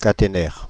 Prononciation
Synonymes chaînette LAC Prononciation Paris: IPA: [ka.te.nɛʁ] France (Île-de-France): IPA: /ka.te.nɛʁ/ Le mot recherché trouvé avec ces langues de source: français Traduction Substantifs 1.